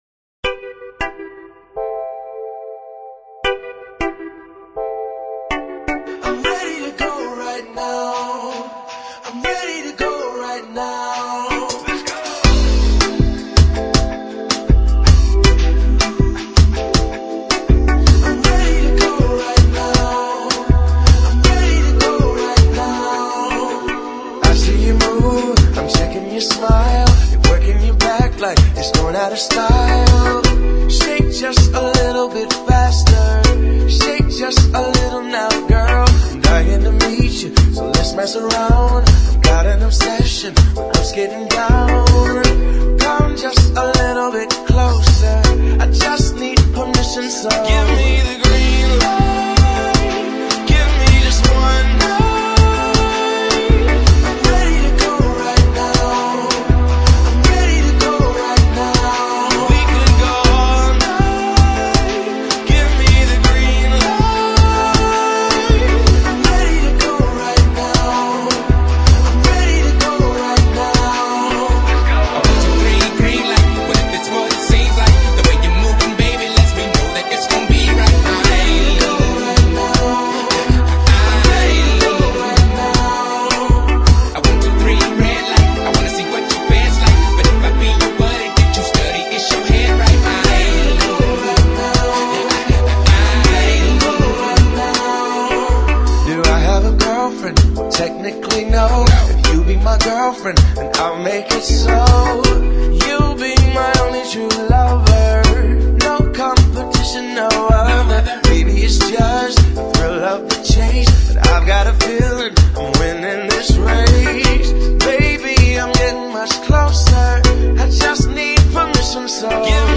Entre-Kizomba